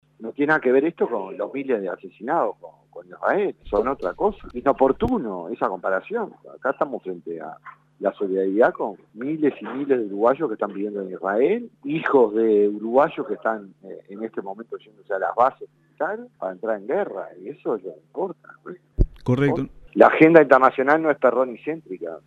Escuche las declaraciones del senador blanco Sebastián Da Silva: